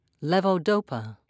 (lee-voe-doe'pa)